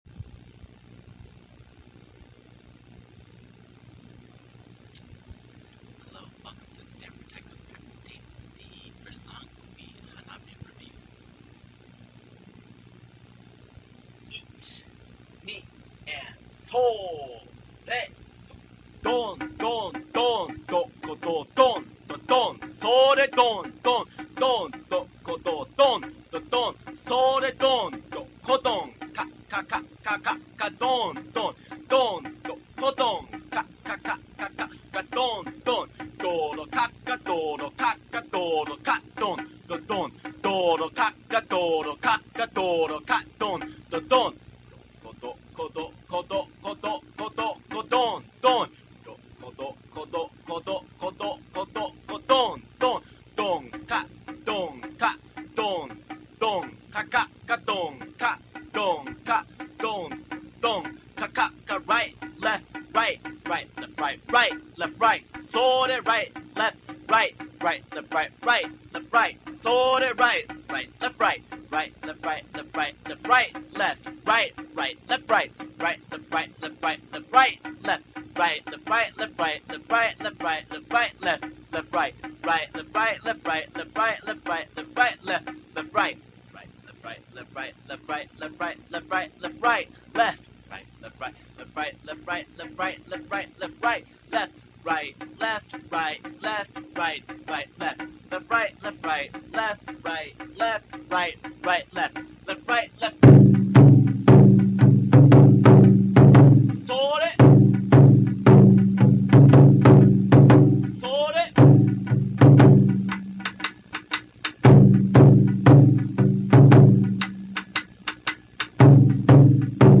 Hanabi is fun and upbeat, with energized solos and moments evoking exploding fireworks.
In Hanabi, the beat is swung.
Each of the five lines are repeated twice.
NOTE: The sticking for Line 5 in the audio recording is incorrect.